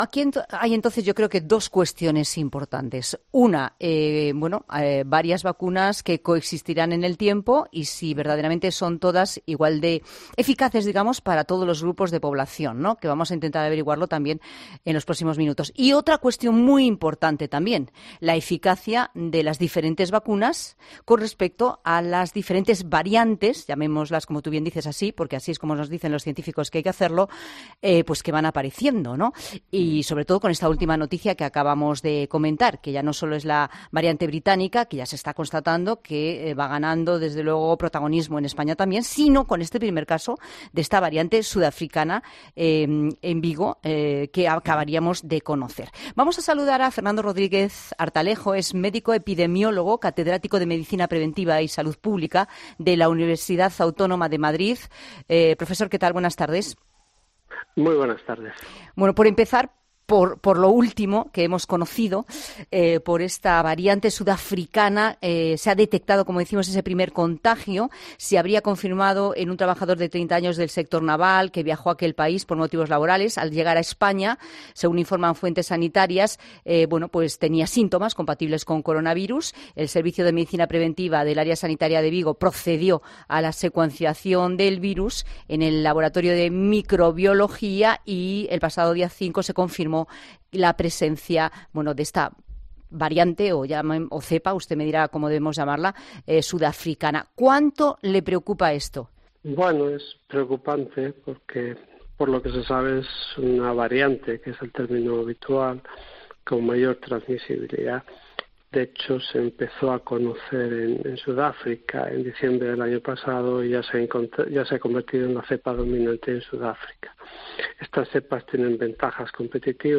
En 'La Tarde' hemos analizado con un experto la llegada de esta cepa a nuestro país